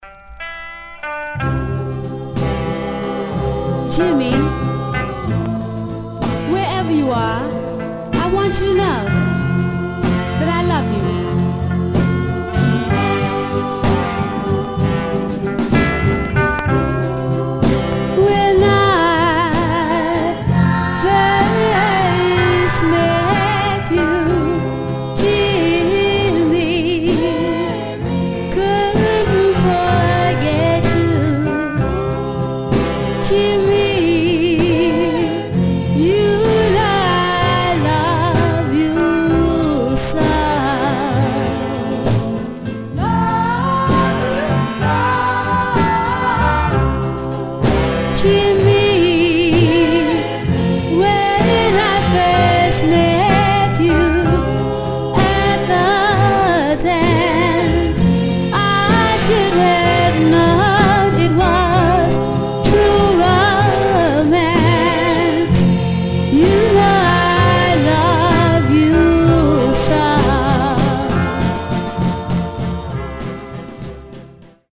Some surface noise/wear Stereo/mono Mono
Black Female Group